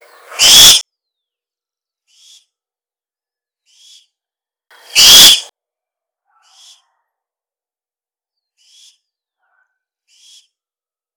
Llamado: emite un chillido desde una percha o en vuelo.
lechuzacampanario.wav